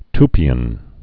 (tpē-ən, t-pē-)